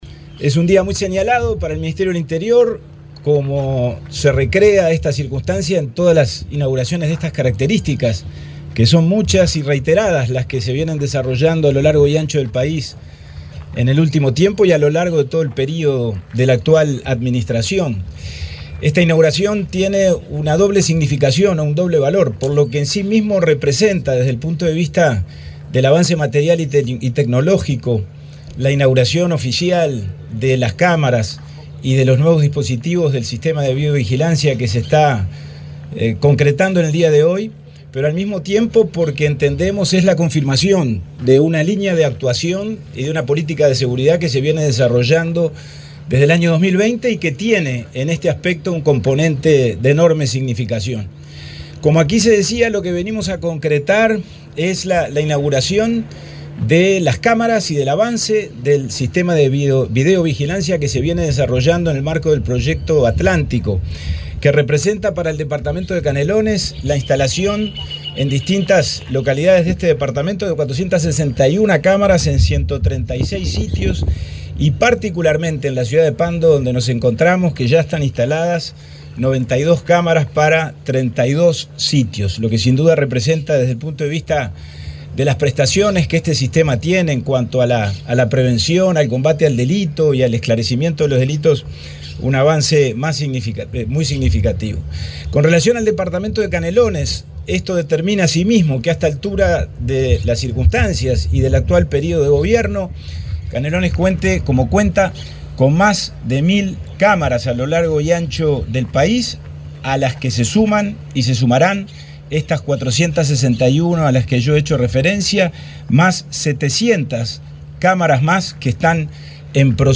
Palabras del subsecretario del Interior, Pablo Abdala
El subsecretario del Interior, Pablo Abdala, participó en la presentación de cámaras de videovigilancia en Pando, departamento de Canelones.